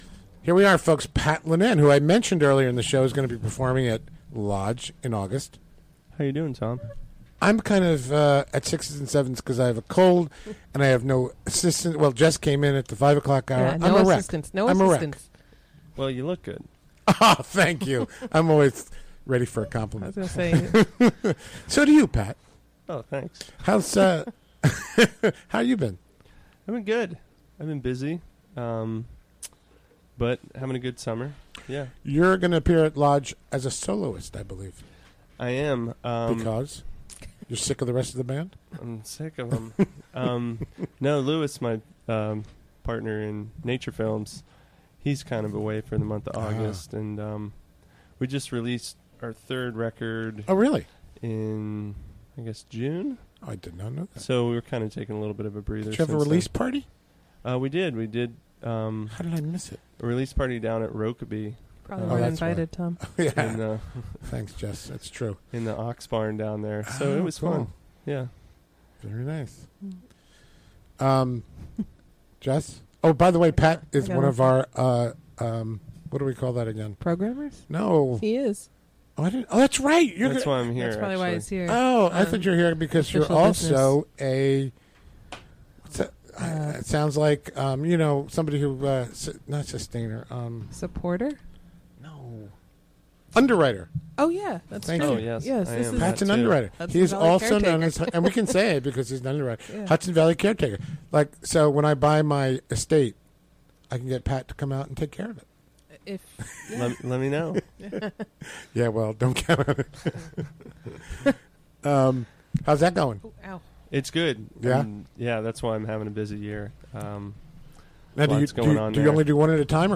Recorded during the WGXC Afternoon Show on Thursday, July 28, 2016.